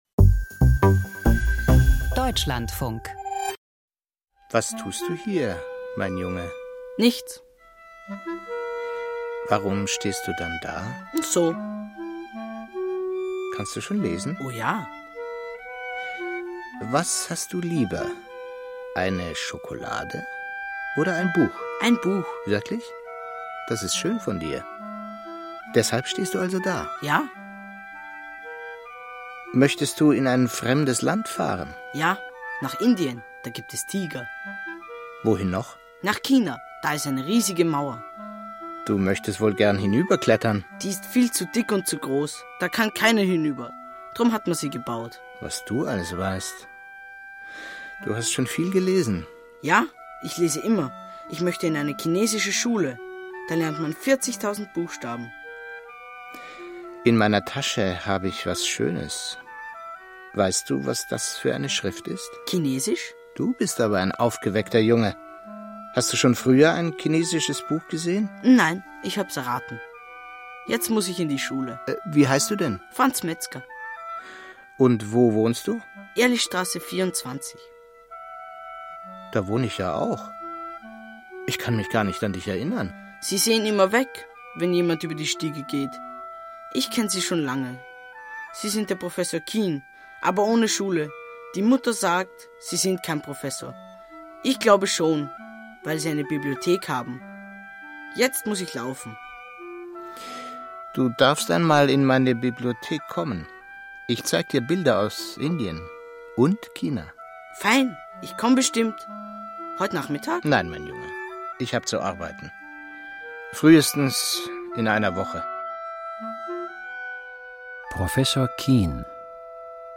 • Philosophisches Hörspiel •